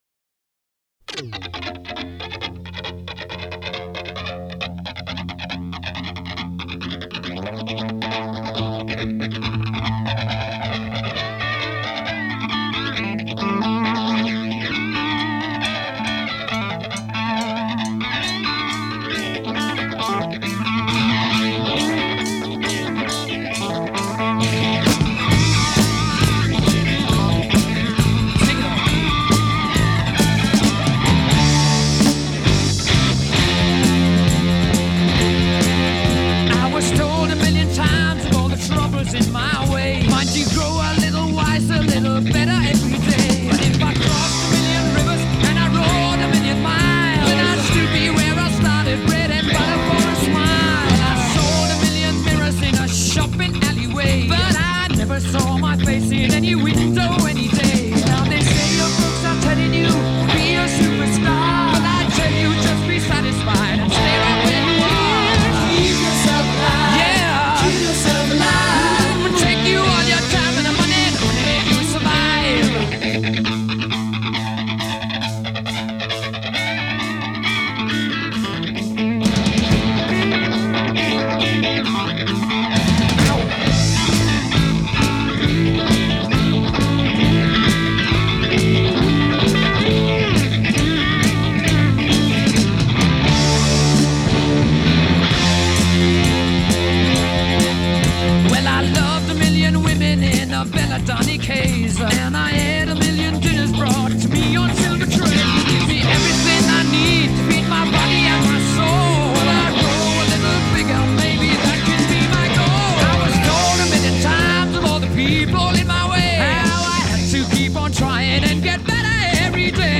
با گیتارهای چندلایه و انرژی پرانرژی
Hard Rock, Glam Rock, Progressive Rock